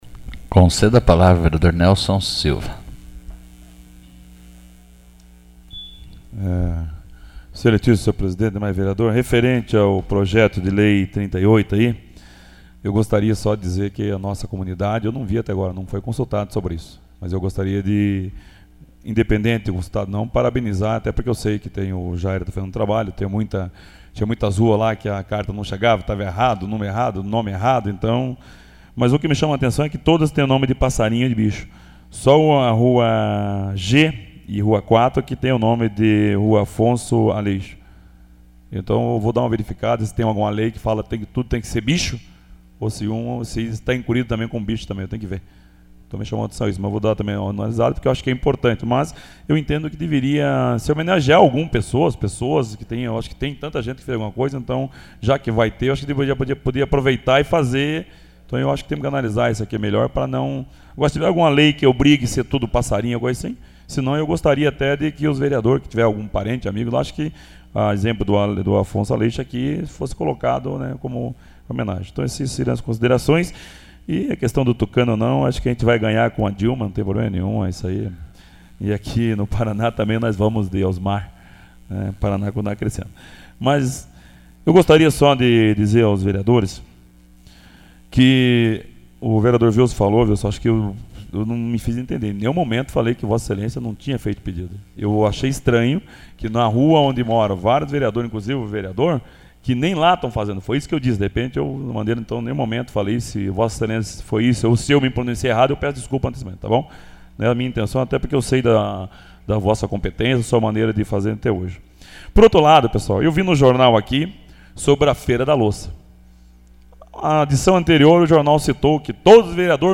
Pronunciamento Pessoal AVULSO 14/09/2010 Nelson Silva de Souza